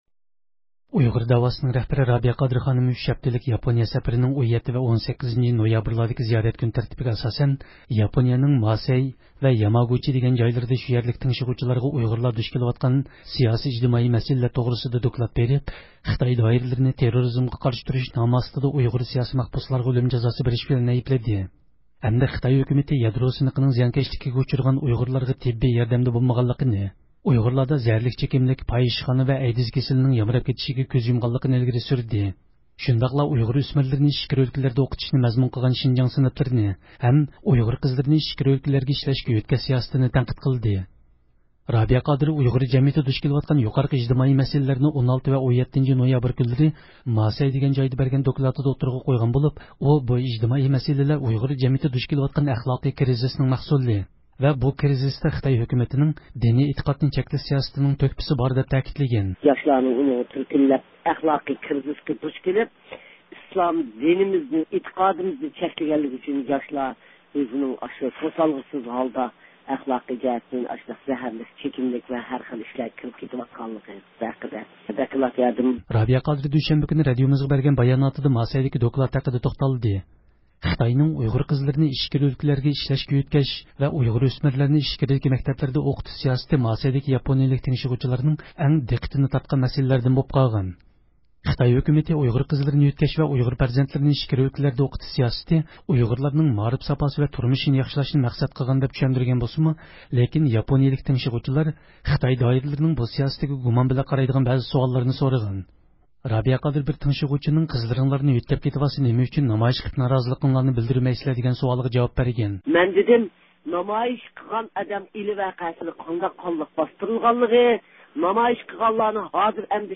رابىيە قادىر دۈشەنبە كۈنى رادىئومىزغا بەرگەن باياناتىدا ماسەيدىكى دوكلاتى ھەققىدە توختالدى.